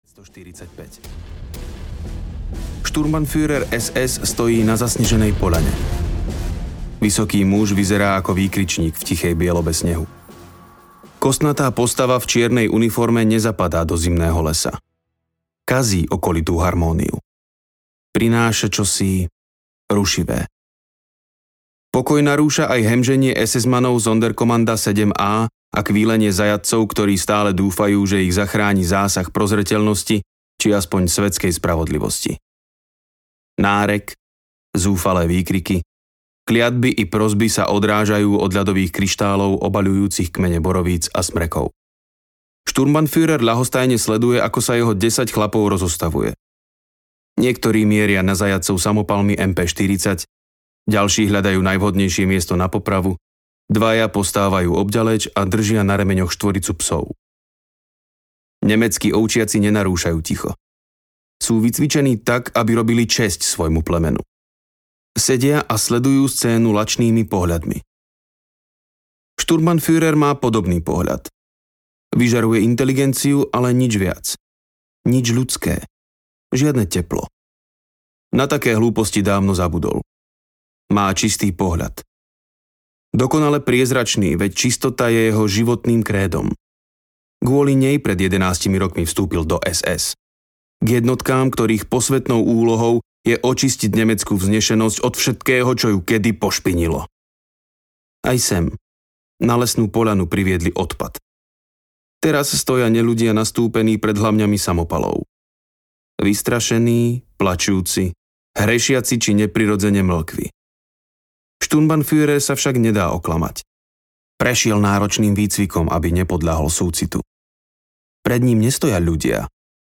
Na smrť audiokniha
Ukázka z knihy